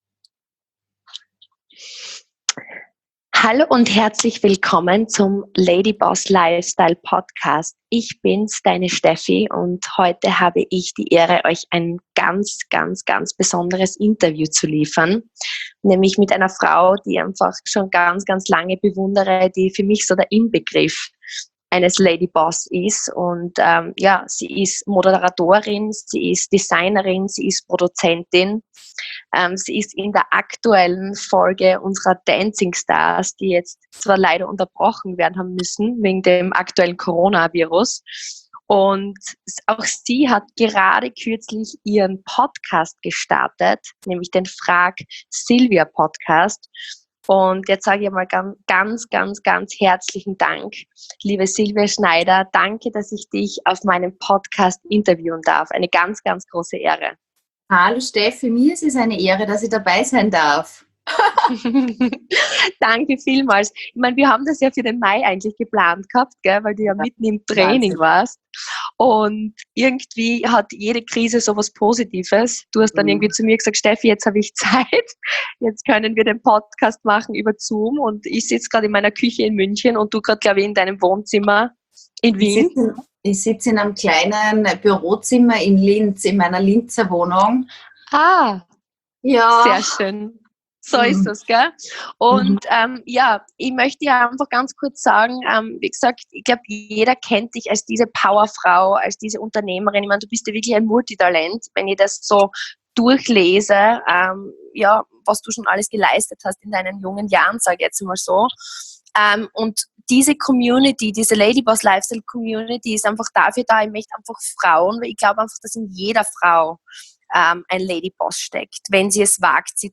INTERVIEW mit: Silvia Schneider!